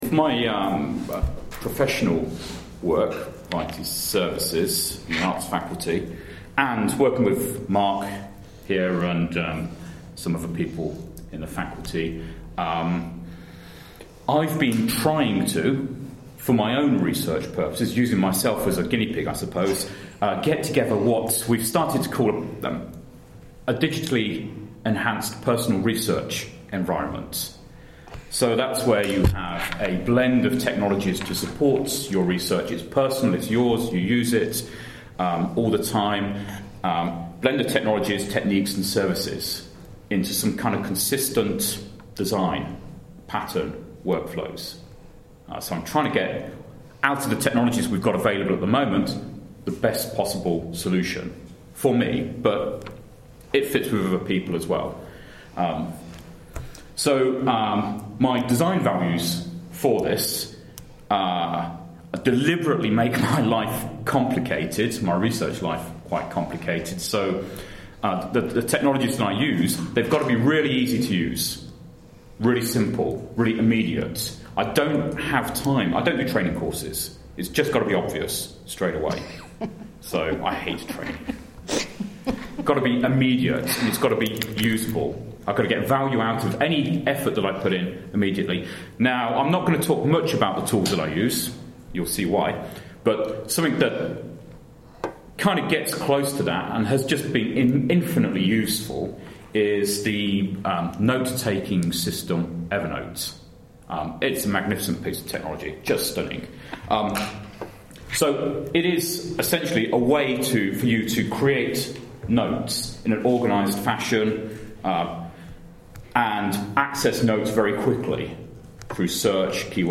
at a Digital Change GPP event